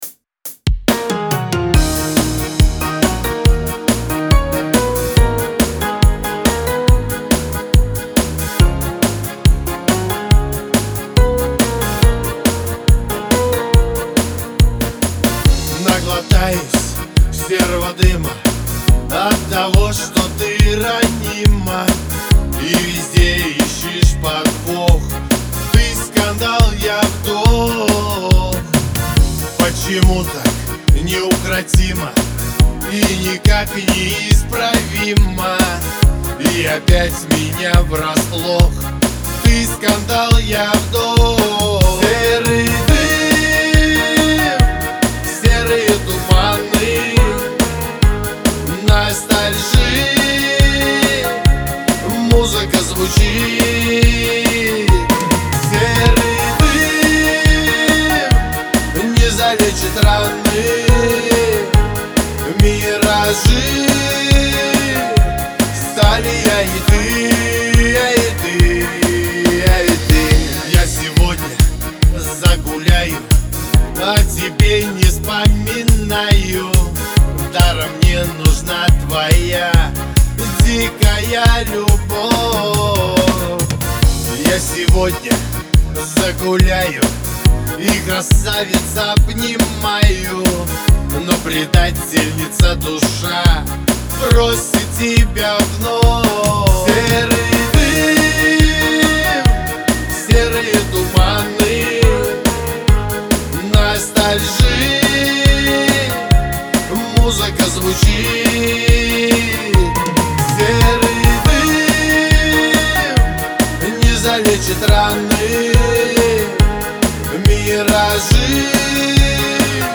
эстрада
грусть
Шансон